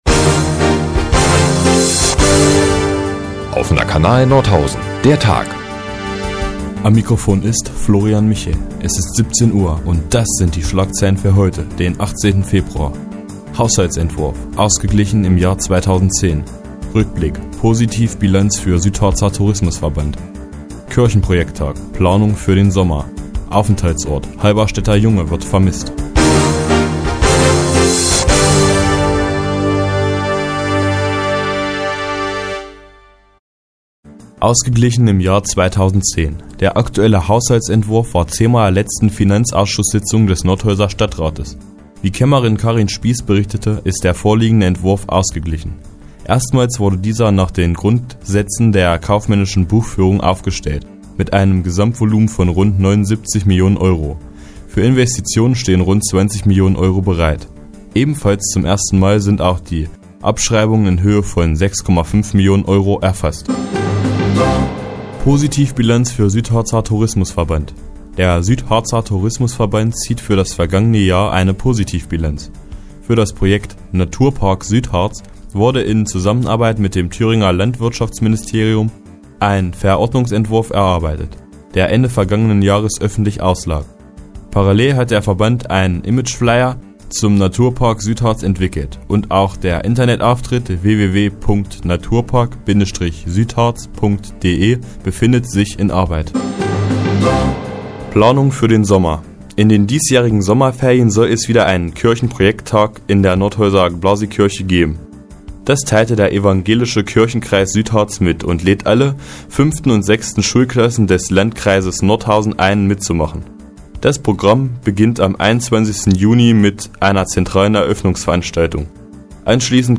Die tägliche Nachrichtensendung des OKN ist nun auch in der nnz zu hören. Heute geht es um den aktuellen Haushaltsentwurf des Nordhäuser Stadtrates und den Kirchen Projektag ind der Blasii-Kirche.